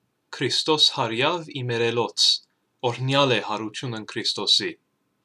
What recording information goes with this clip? April 12, 2020 -Easter Sunday